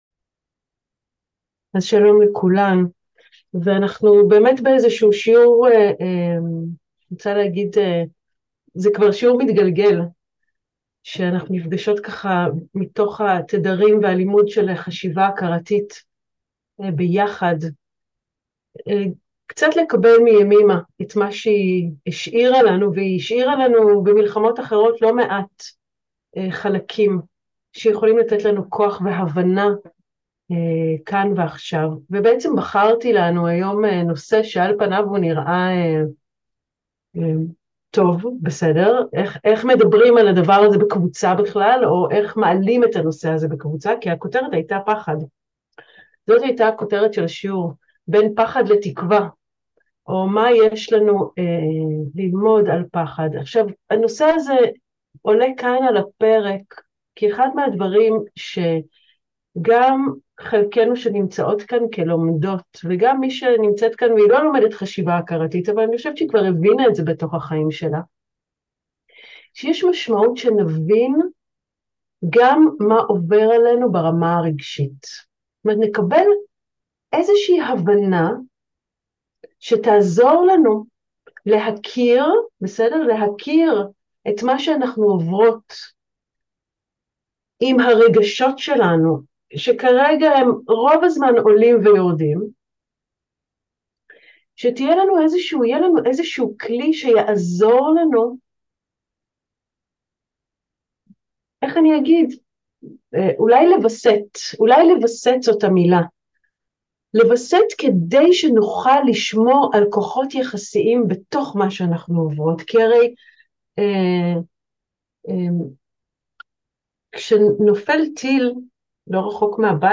מתוך שיעורים פתוחים לימי מלחמה, שיחה על פחד